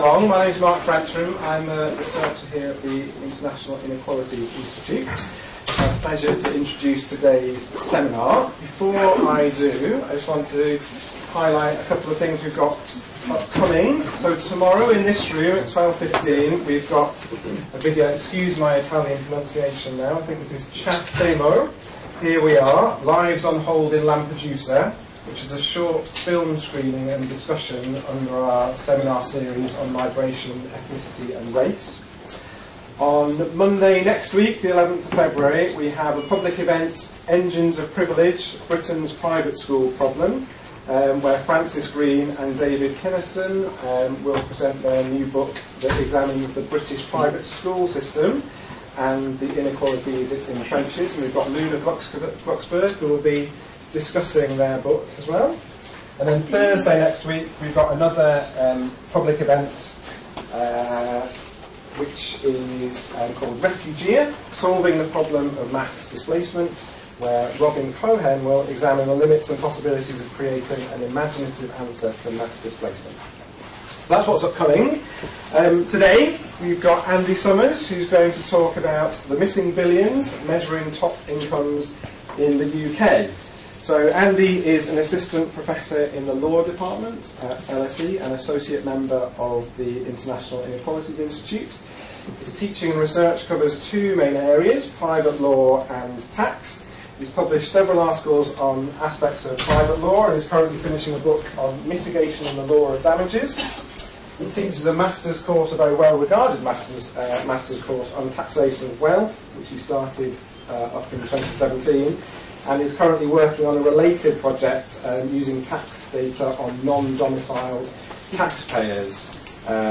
LSE III, Inequalities seminar